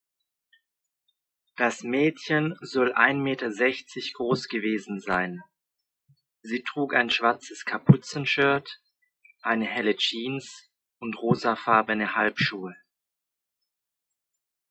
Aussprache: einen Text lesen